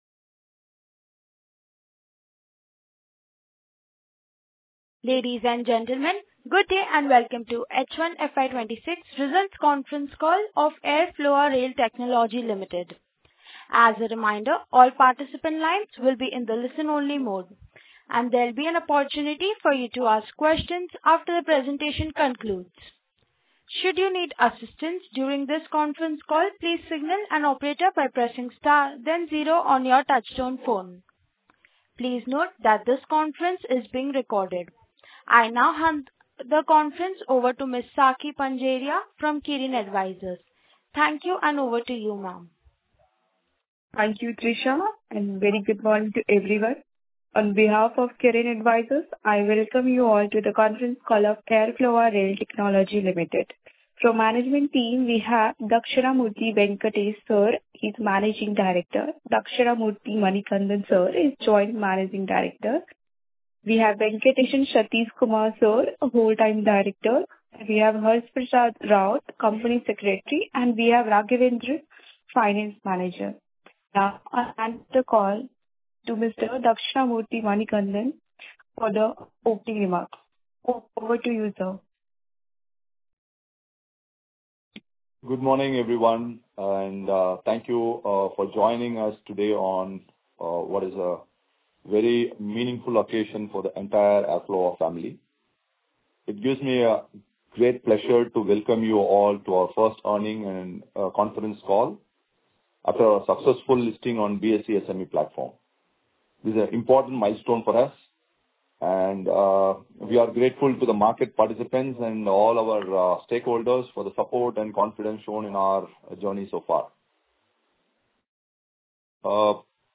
H1 FY26 Earnings Call Audio Recording- Airfloa Rail Technology Limited.mp3